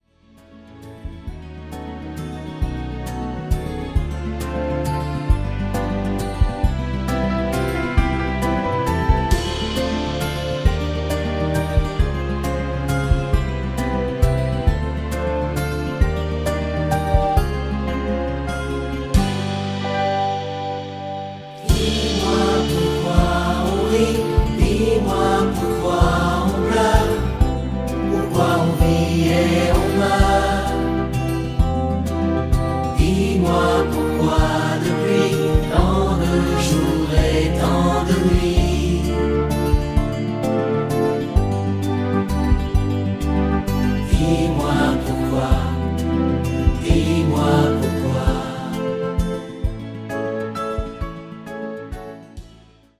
avec choeurs originaux